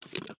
InsertBattery_2.wav